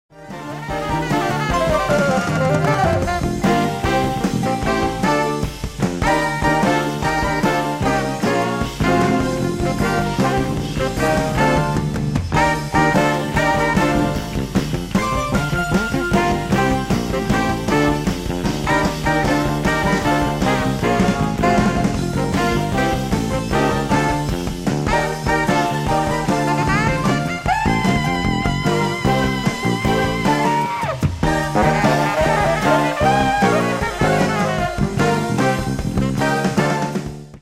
LIVE AT UMBRIA JAZZ FESTIVAL, PERUGIA, ITALY
SOUNDBOARD RECORDING